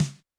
Drums_K4(52).wav